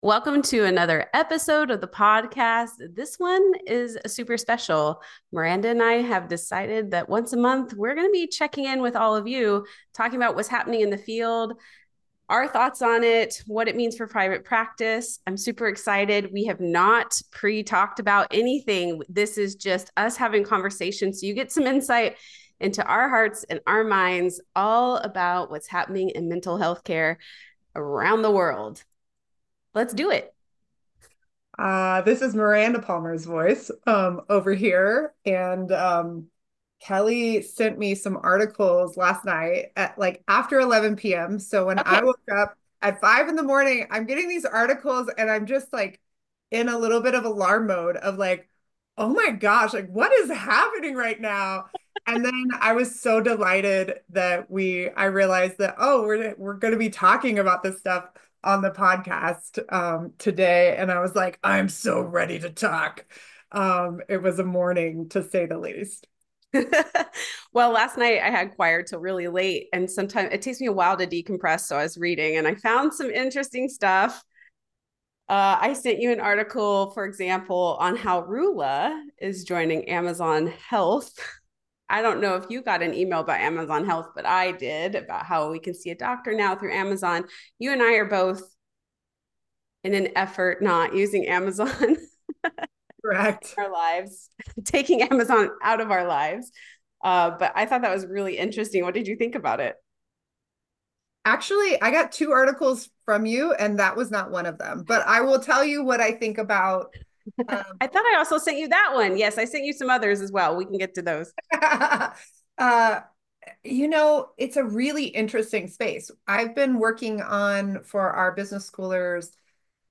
In this kickoff chat